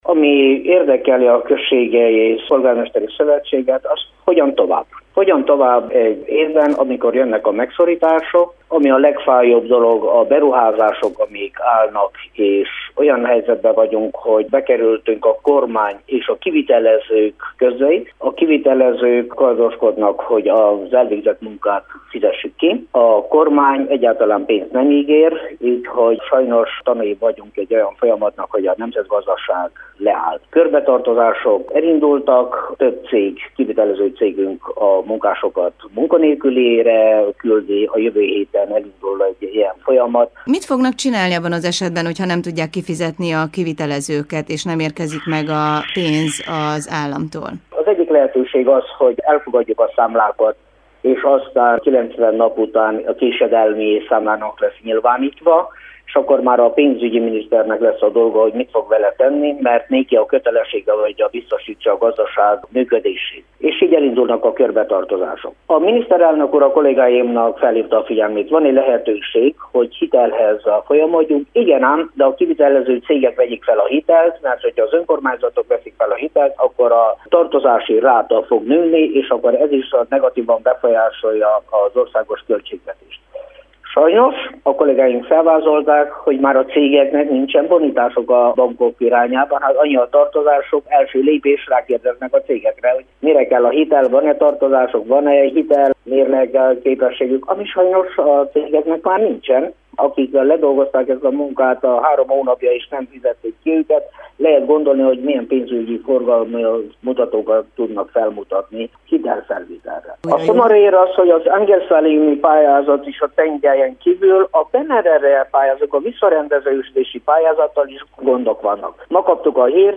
Nincs pénz megkezdett munkálatok kivitelezésére, és a kormányfővel való találkozás sem volt eredményes. Boncidai Csaba szilágyperecseni polgármestert kérdeztük.